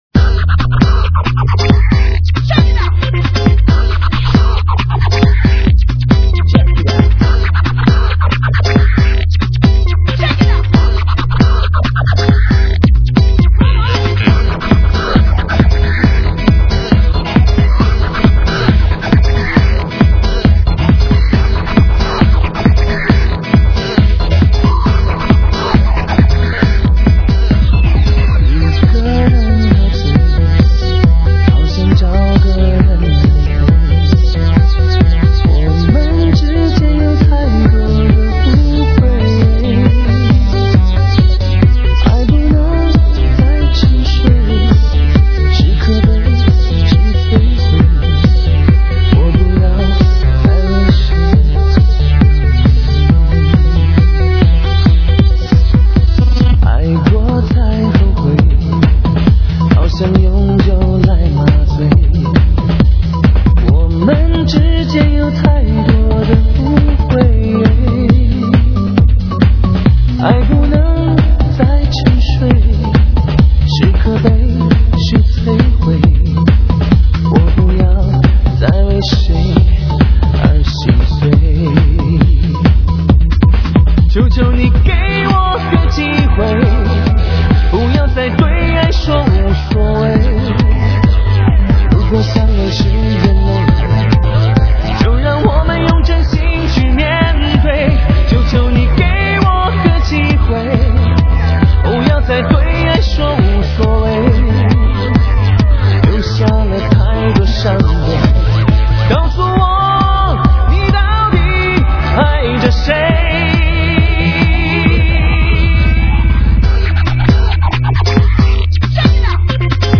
超级狂野
流行前线时尚节拍